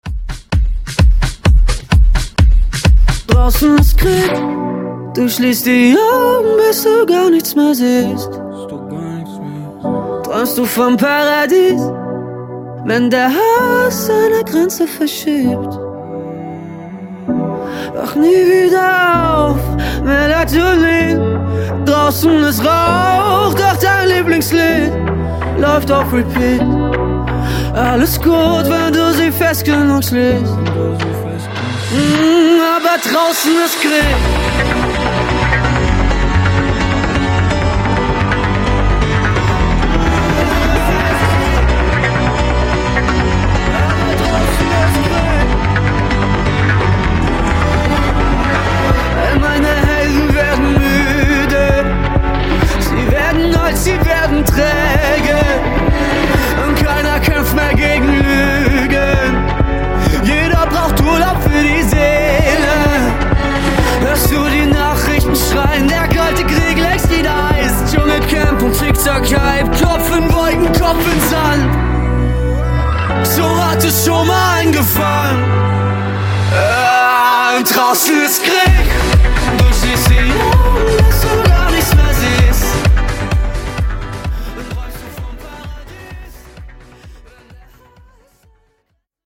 Genre: DANCE
Clean BPM: 128 Time